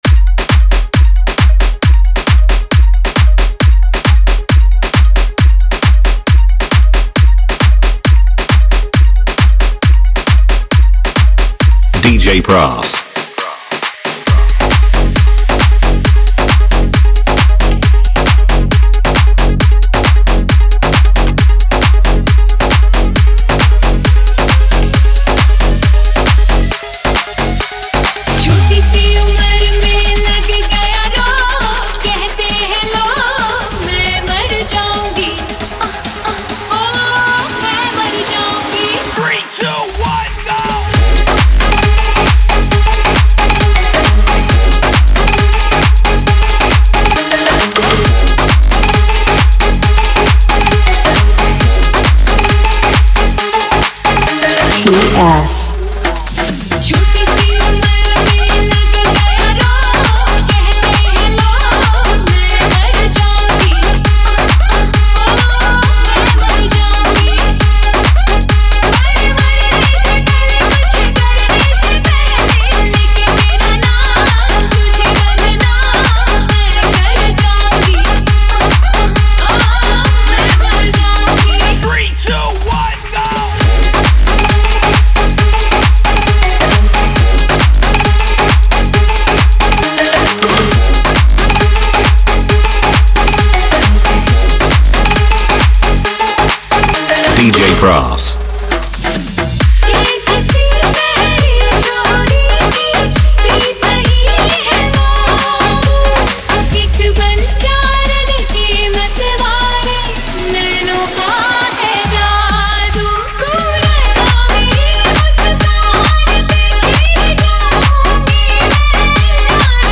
tiktok mashup